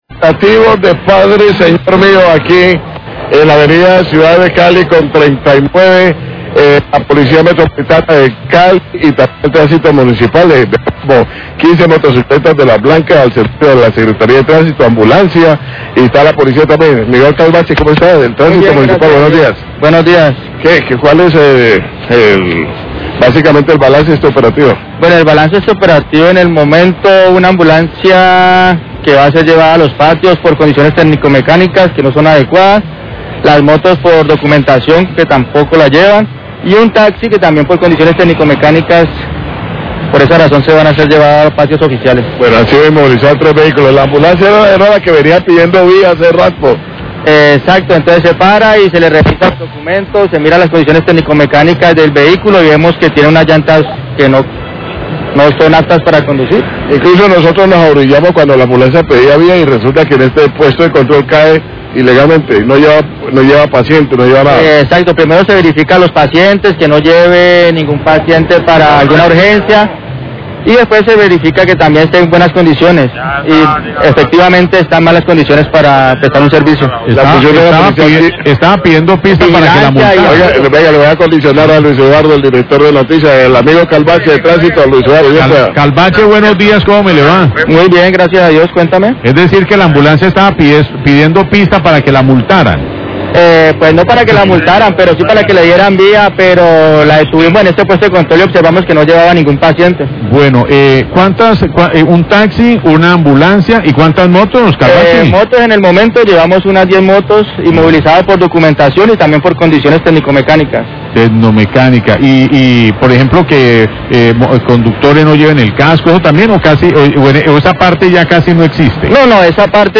Radio
Guarda de Tránsito informa sobre los operativos de tránsito que se están realizando en la ciudad de Cali.  Informo sobre la inmovilización de vehículos en un operativo en la ciudad de cali con carrera 39, donde se detuvo a una ambulancia que iba sin paciente y con condiciones tecnicomecanicas no aptas.